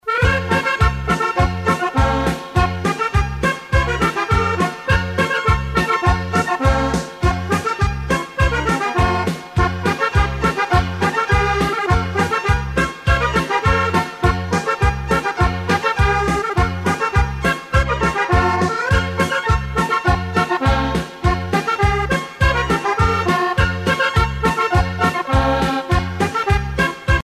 danse : polka piquée
Pièce musicale éditée